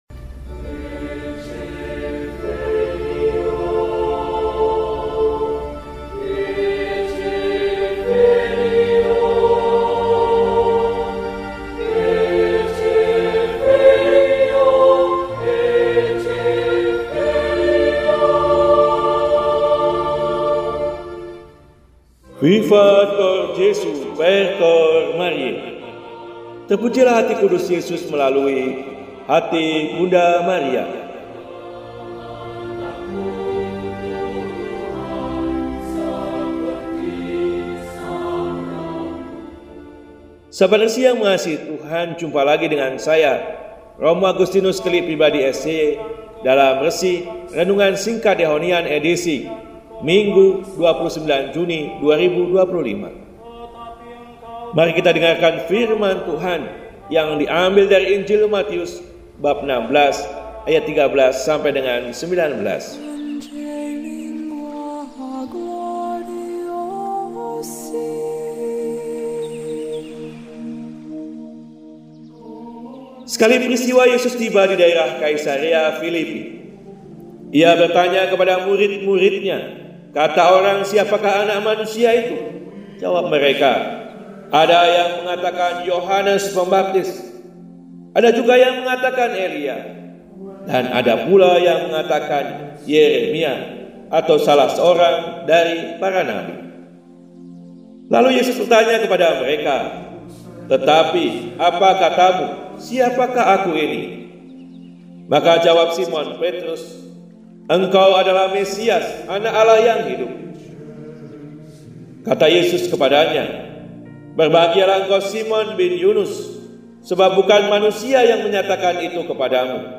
Minggu, 29 Juni 2025 – Hari Raya St. Petrus dan Paulus – RESI (Renungan Singkat) DEHONIAN